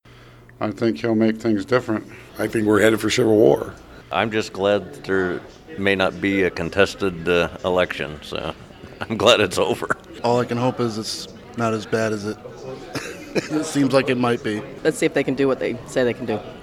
REACTION OF PEOPLE IN MANCHESTER, NEW HAMPSHIRE
MANCHESTER-NH-MONTAGE-OF-MOS-WEDNESDAY-MORNING.mp3